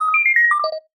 parent-tool-sound-success.wav